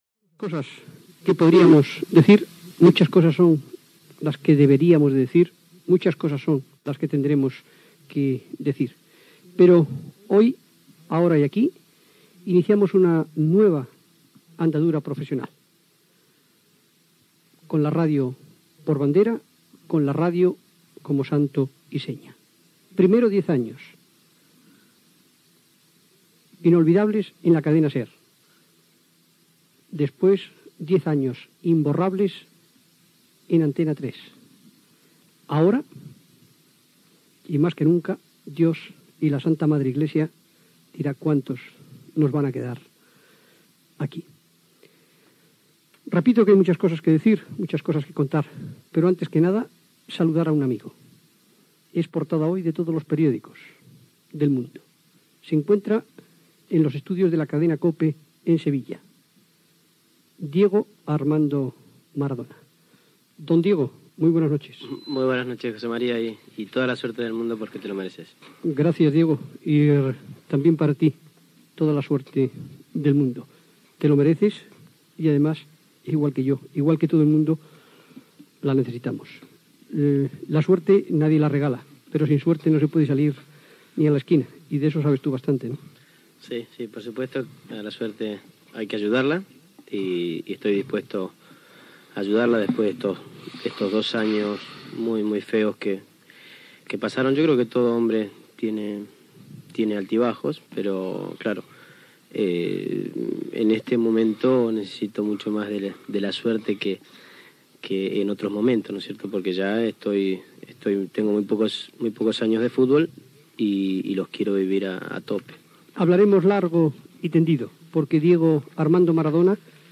047bfaded9270a521b9d6dde97c4f68020d1979a.mp3 Títol Cadena COPE Emissora COPE Miramar Cadena COPE Titularitat Privada estatal Nom programa Supergarcía Descripció Presentació de la primera edició del programa a la Cadena COPE, diàleg amb Diego Armando Maradona que es trobava a Sevilla, agraïment de José María García a les persones que estan al seu costat i record als professionals d'Antena 3 Ràdio, publicitat, presentació dels col·laboradors que estan a Sevilla amb Diego Armando Maradona. Represa de l'entrevista a Maradona tot escoltant un tango que ell va cantar Gènere radiofònic Esportiu